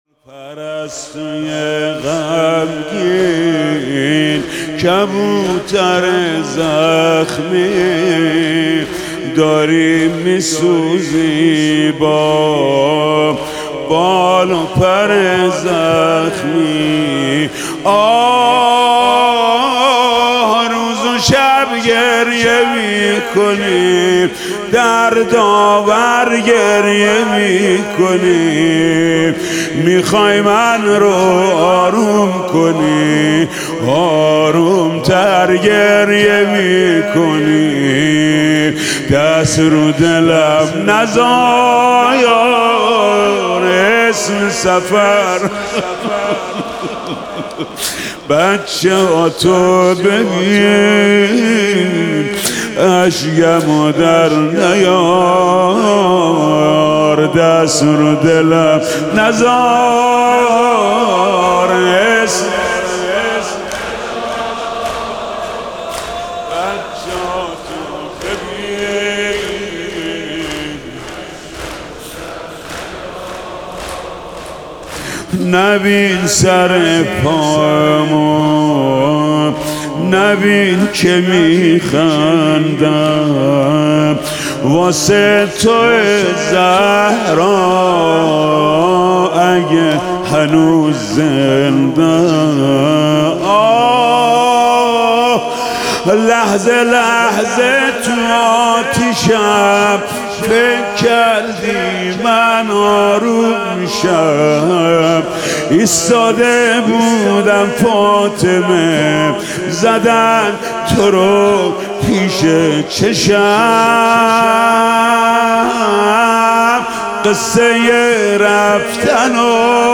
متن مداحی